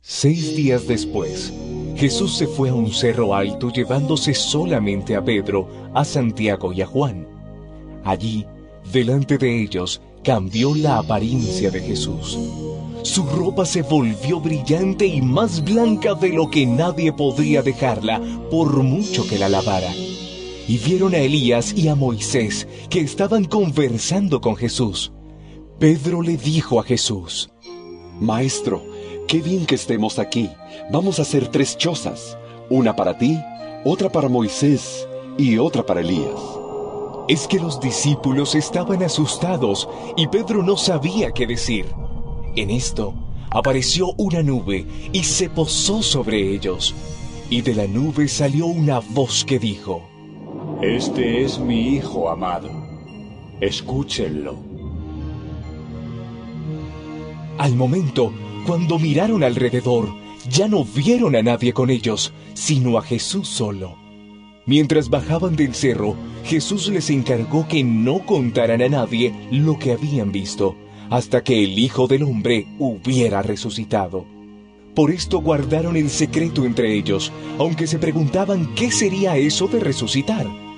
EVANGELIO EN AUDIO